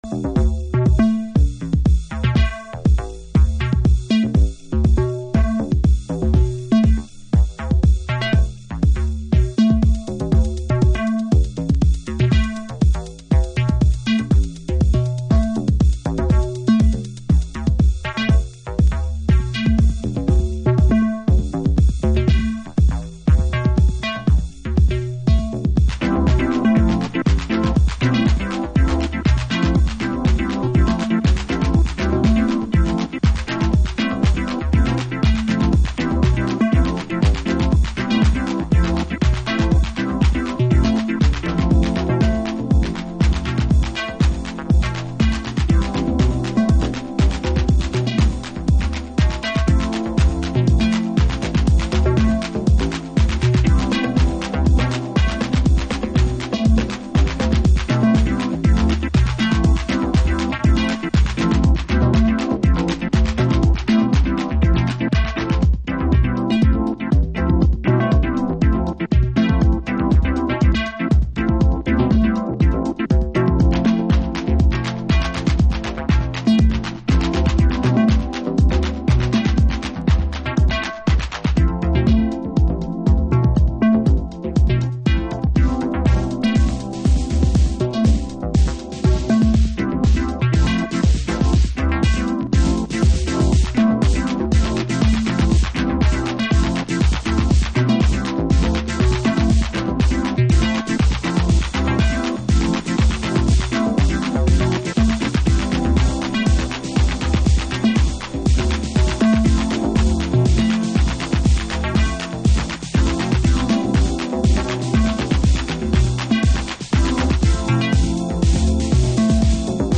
Chicago Oldschool / CDH